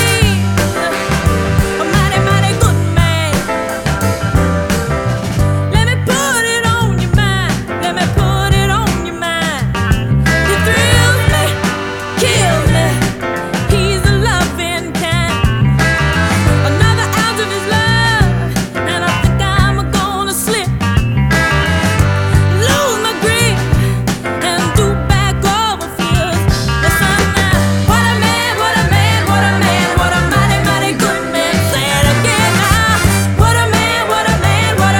Жанр: Соул